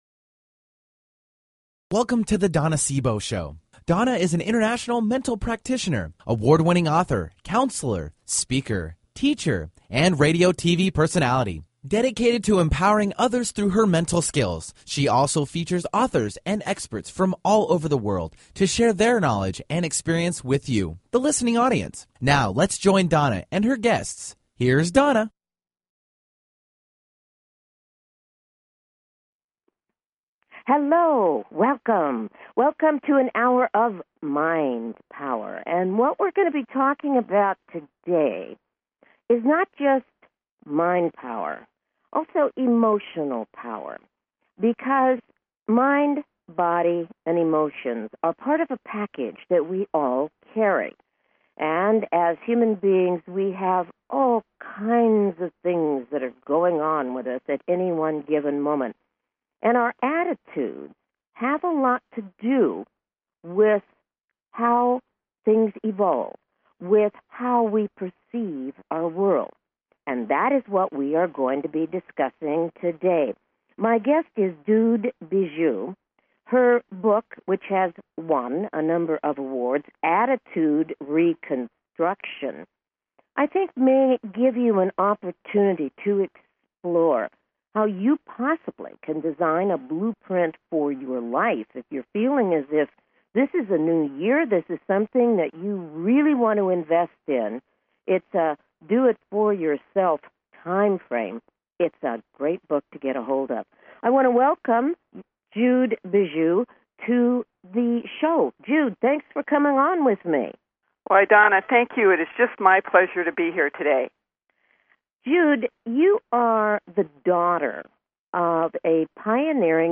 Her interviews embody a golden voice that shines with passion, purpose, sincerity and humor.
Talk Show
Callers are welcome to call in for a live on air psychic reading during the second half hour of each show.